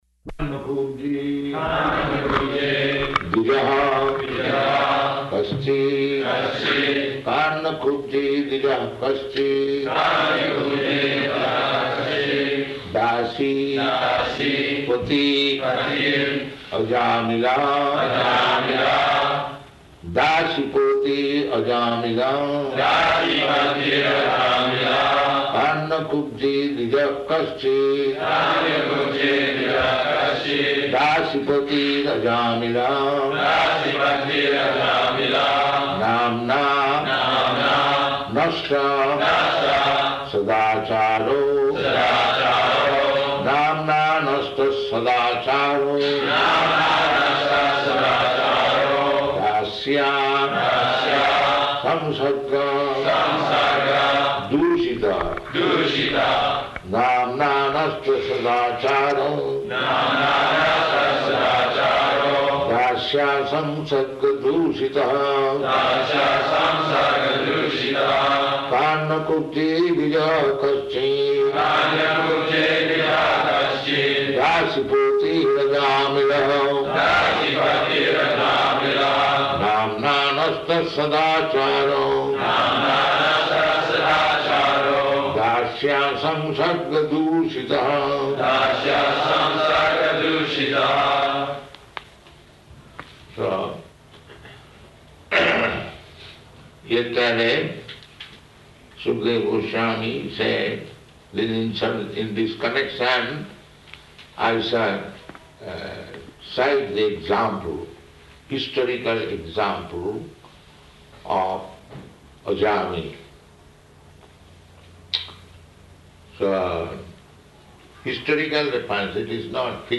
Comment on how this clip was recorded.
Location: Honolulu